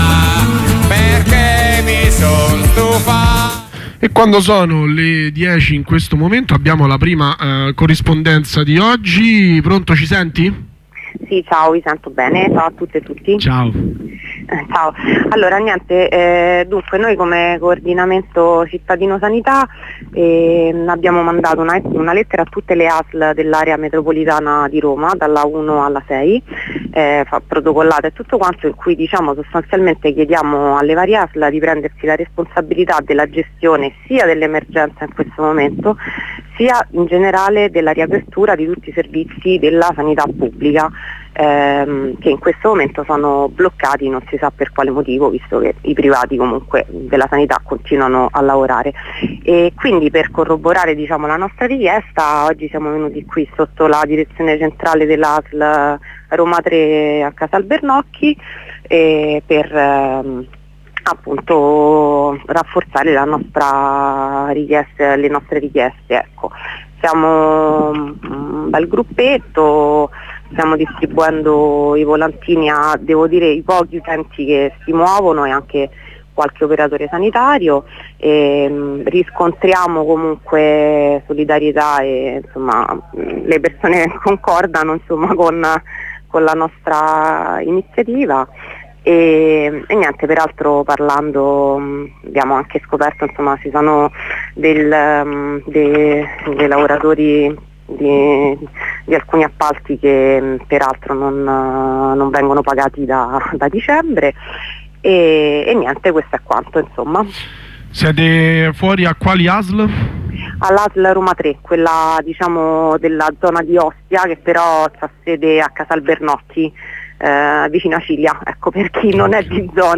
Corrispondenze con due compagne del Coordinamento Cittadino Sanità per raccontare le azioni alle sedi delle ASL territoriali per consegnare la lettera di denuncia sul diritto alla salute nella nostra città ai tempi del Covid-19.